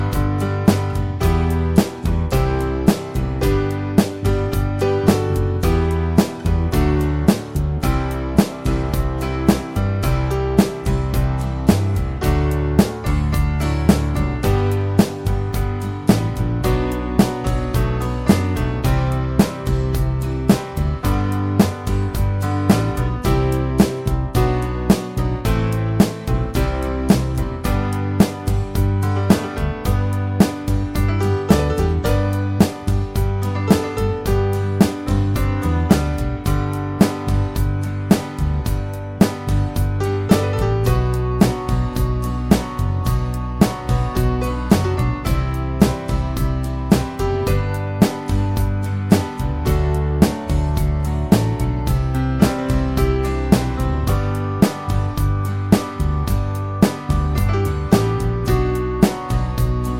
no Backing Vocals Soft Rock 5:15 Buy £1.50